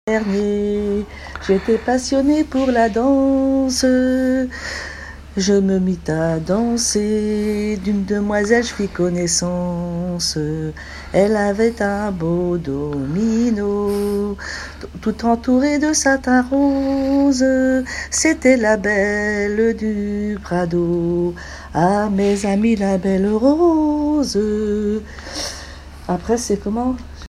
Genre strophique
formulettes enfantines, chansons en français et en breton
Pièce musicale inédite